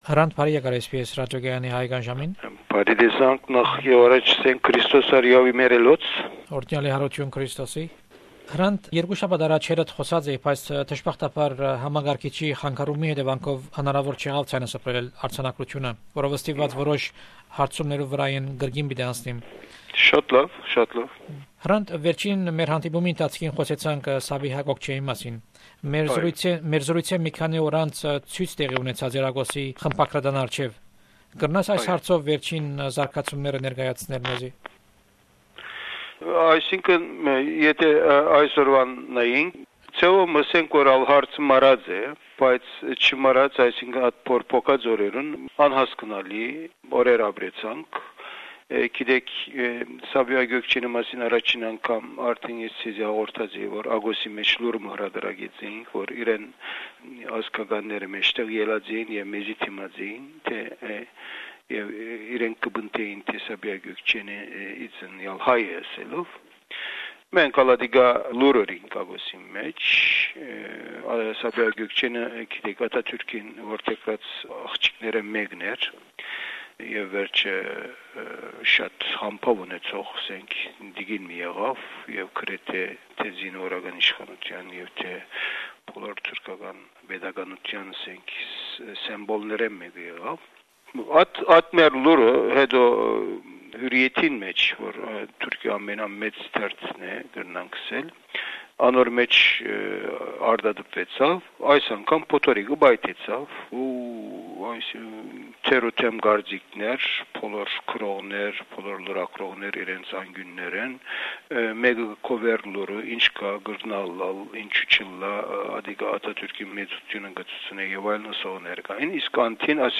In memory of the 10th anniversary of the assassination of Hrant Dink, we bring you the interviews he gave to SBS Radios Armenian program. This interview was done in April 2004.